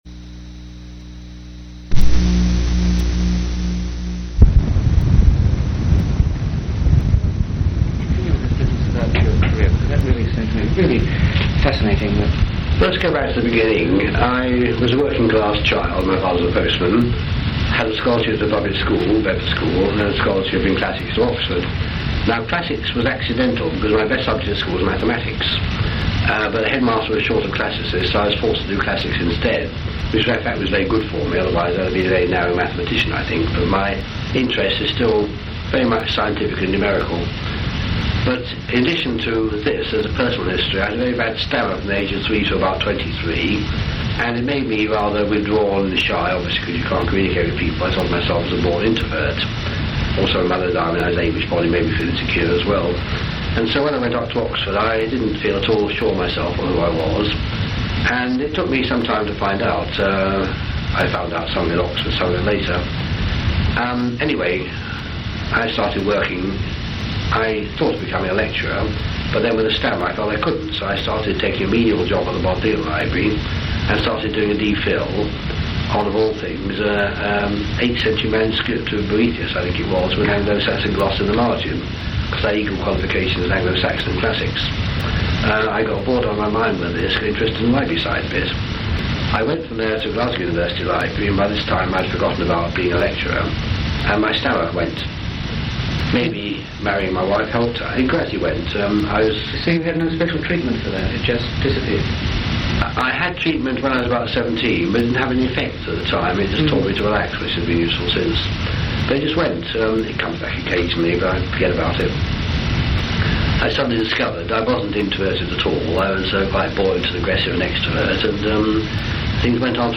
Oral histories
Place of interview England--Harrogate